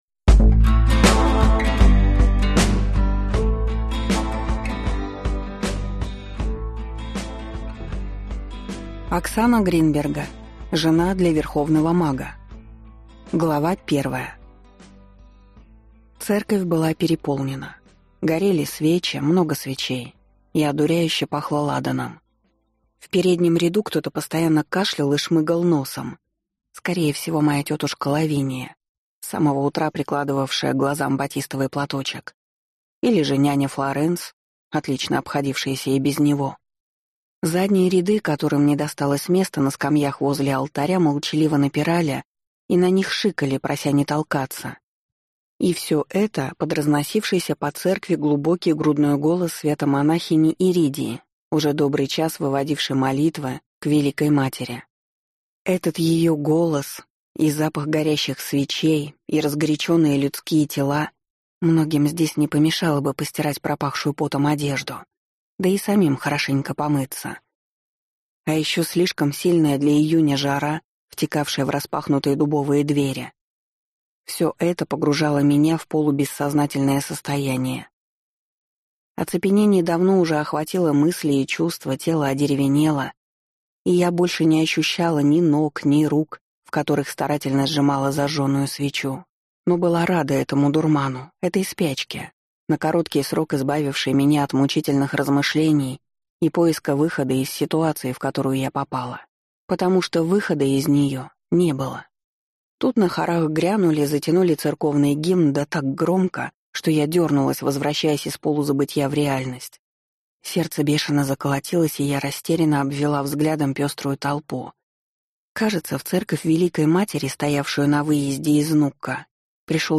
Aудиокнига Жена для Верховного мага